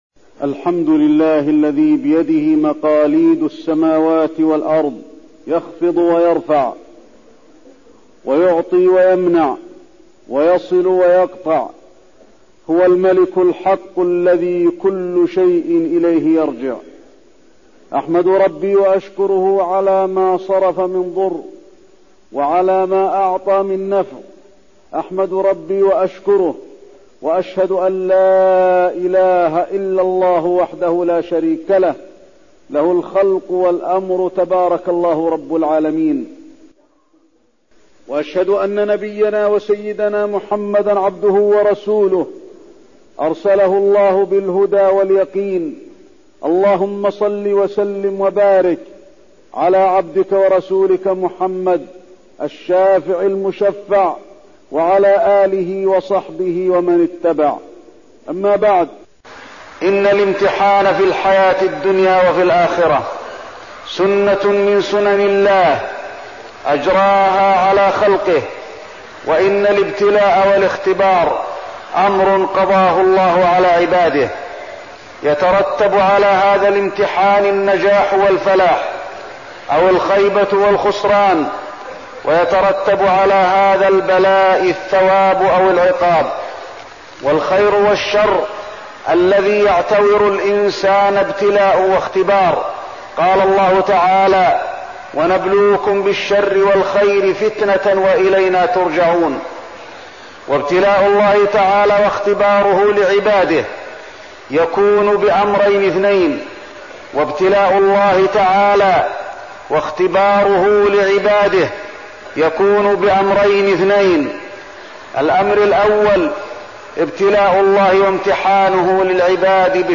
تاريخ النشر ٨ محرم ١٤١٥ هـ المكان: المسجد النبوي الشيخ: فضيلة الشيخ د. علي بن عبدالرحمن الحذيفي فضيلة الشيخ د. علي بن عبدالرحمن الحذيفي الابتلاء